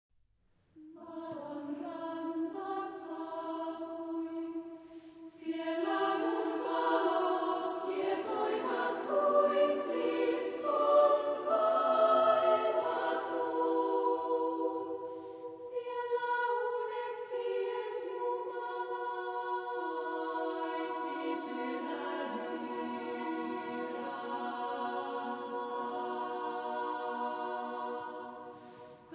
Género/Estilo/Forma: Profano
Tipo de formación coral: SSAA  (4 voces Coro femenino )
Ref. discográfica: Internationaler Kammerchor Wettbewerb Marktoberdorf